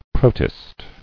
[pro·tist]